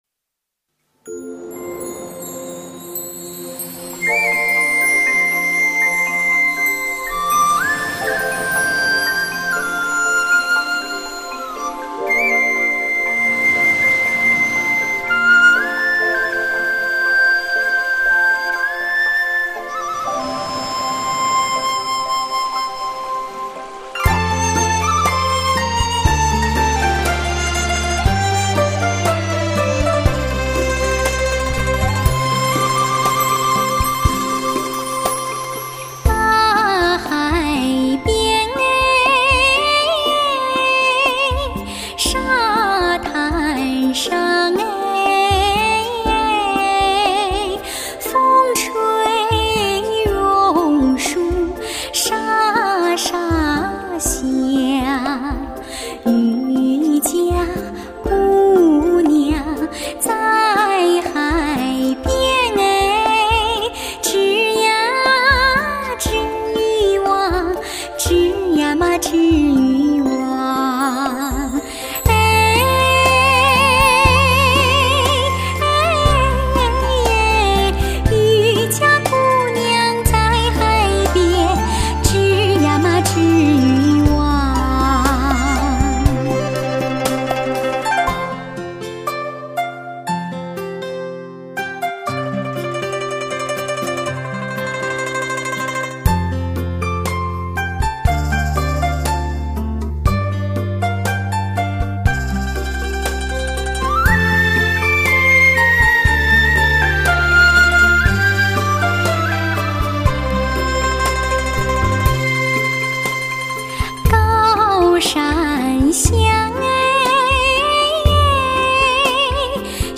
唱片类型：流行音乐
如百灵鸟般水灵优美的动人声线，
“中国风”音乐美感的完美本色，